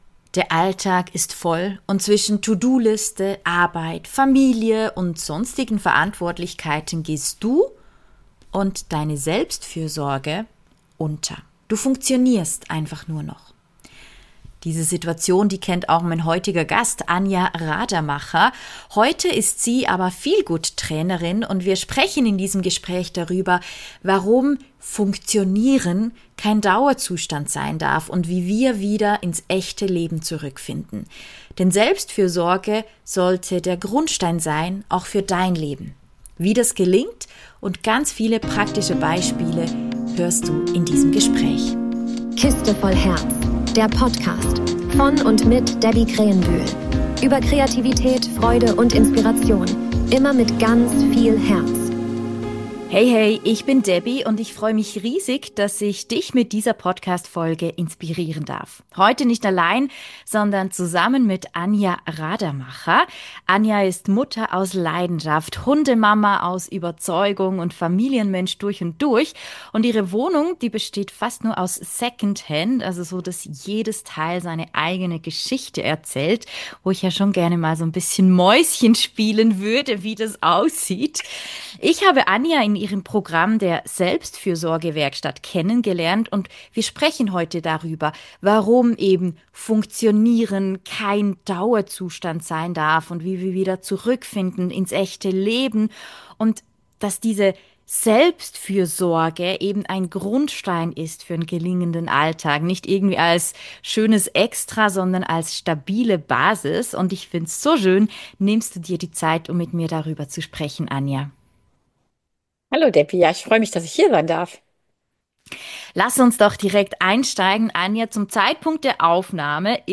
Funktionieren ist kein Dauerzustand: Im Gespräch über Selbstfürsorge